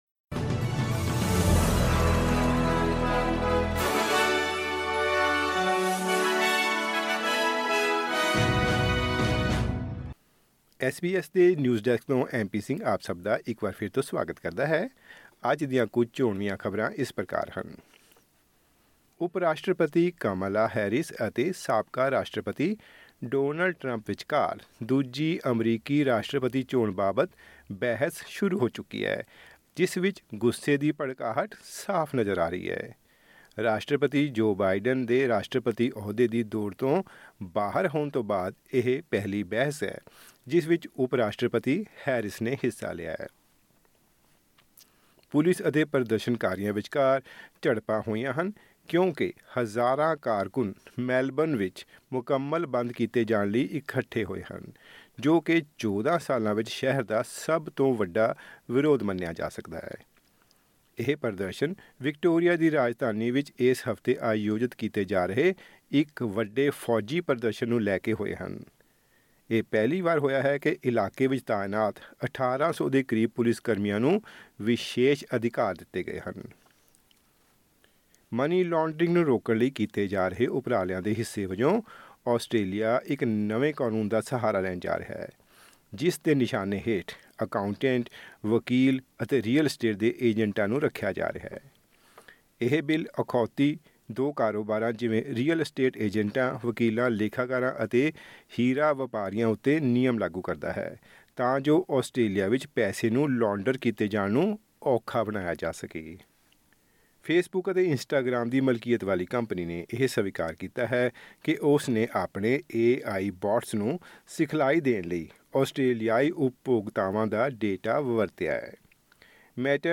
ਐਸ ਬੀ ਐਸ ਪੰਜਾਬੀ ਤੋਂ ਆਸਟ੍ਰੇਲੀਆ ਦੀਆਂ ਮੁੱਖ ਖ਼ਬਰਾਂ: 11 ਸਤੰਬਰ 2024